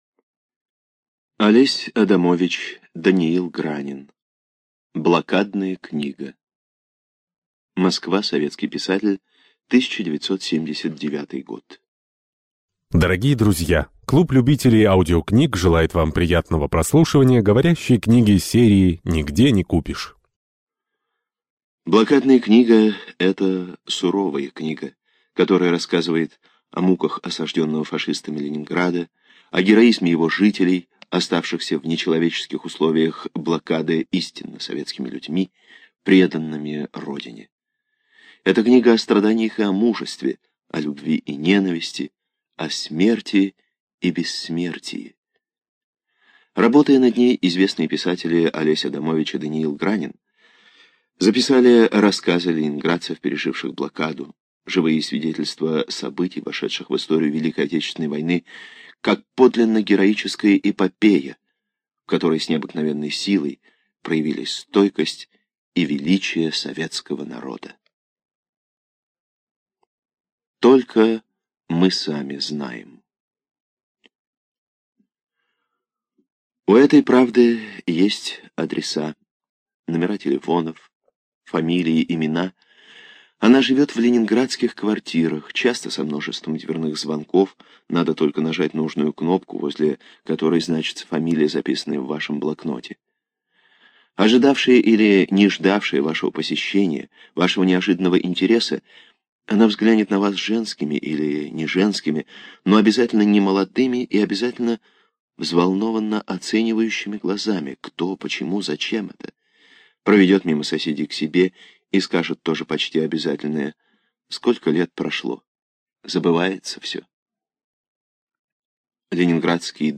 Аудиокнига Блокадная книга
Качество озвучивания весьма высокое.